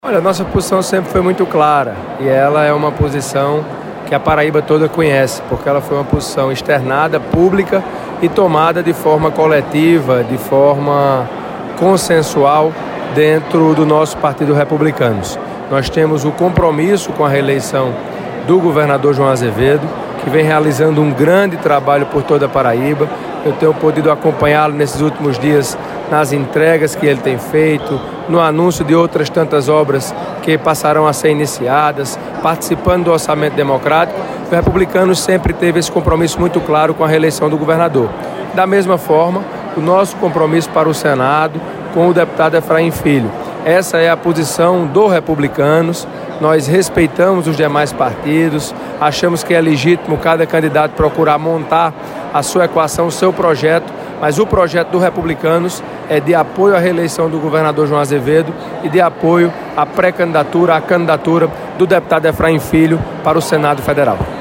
Os comentários de Motta foram registrados nesta segunda-feira (13/06), pelo programa Correio Debate, da 98 FM de João Pessoa.